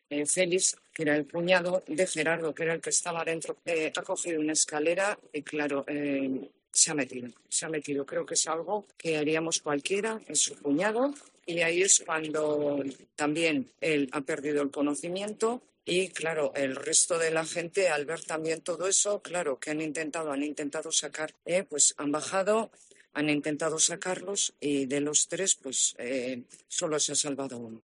La alcaldesa de Lanciego, Itziar de Álava, explica la tragedia que se ha vivido en la bodega